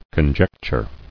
[con·jec·ture]